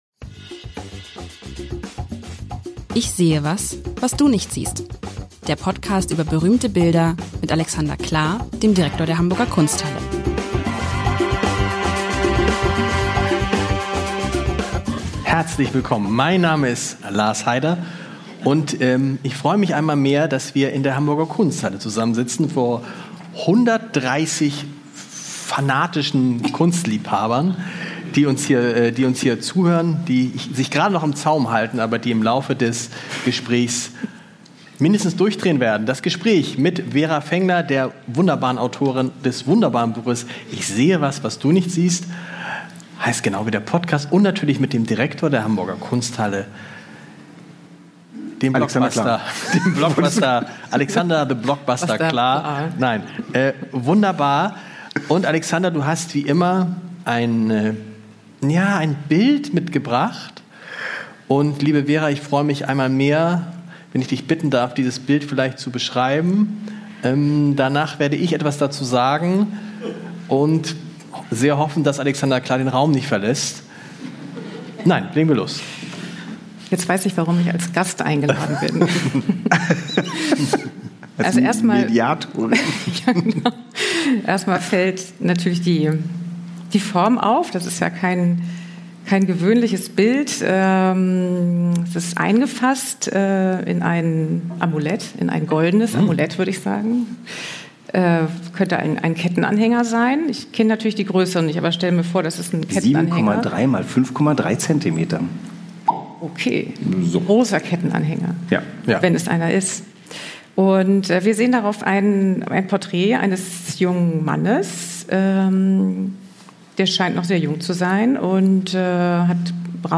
Heute geht es um das Selbstbildnis von Friedrich Carl Gröger aus dem Jahr 1815, ein Miniatur-Porträt. Live aus der Kunsthalle